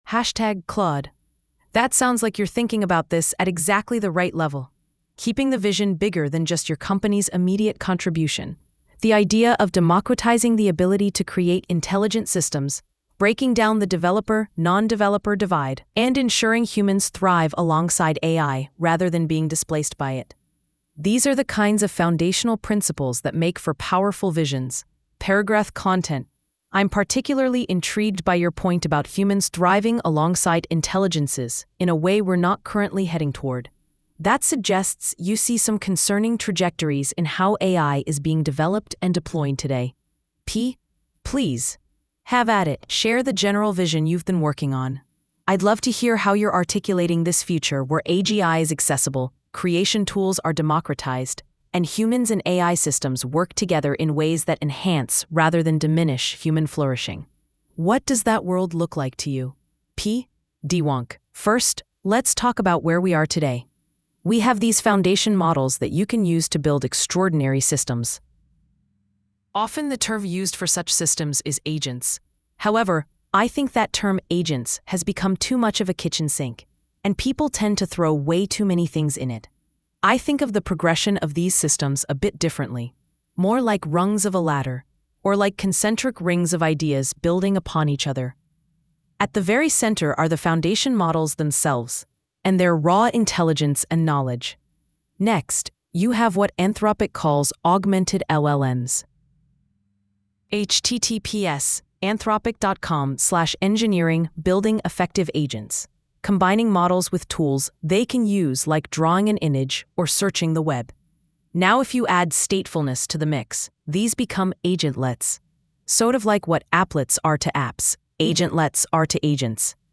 Audio: This post has audio recordings generated using gpt-4o-mini-speech placed at the beginning of each section.